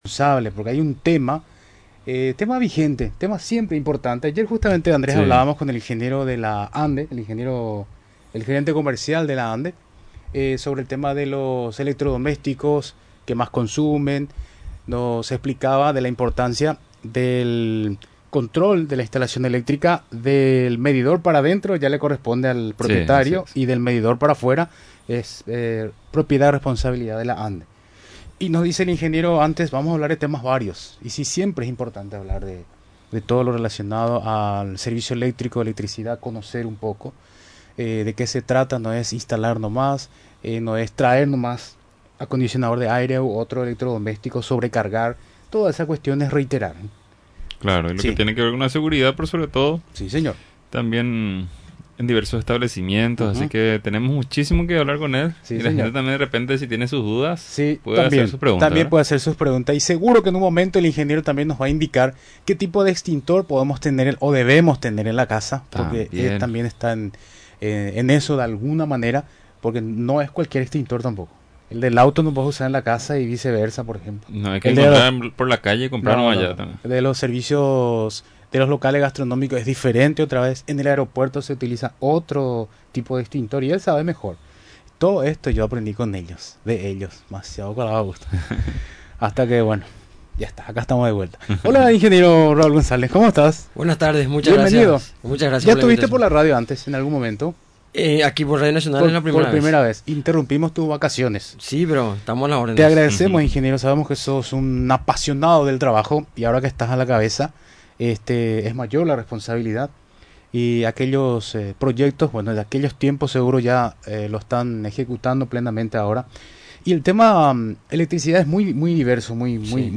Además, durante su visita a los estudios de Radio Nacional del Paraguay, explicó que los extintores para su uso, deben contar con el sello de la institución.